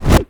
pgs/Assets/Audio/Magic_Spells/casting_charge_whoosh_buildup7.wav
A=PCM,F=96000,W=32,M=stereo
casting_charge_whoosh_buildup7.wav